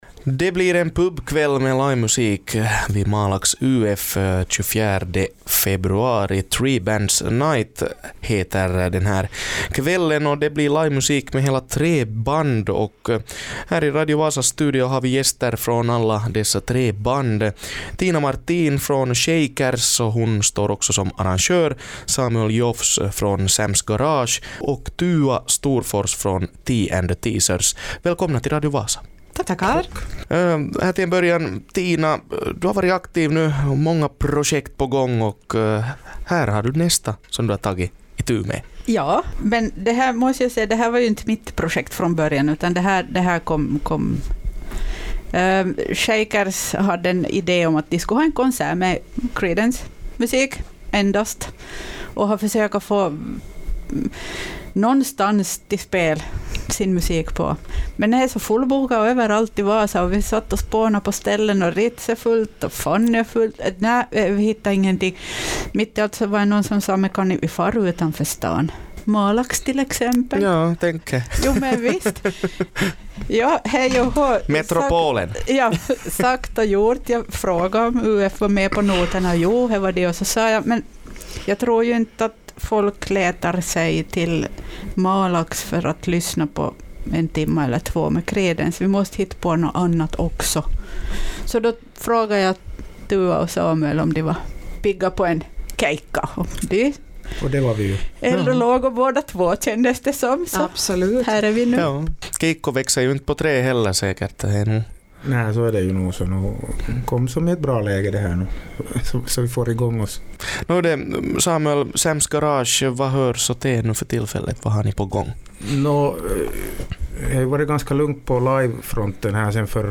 intervjuas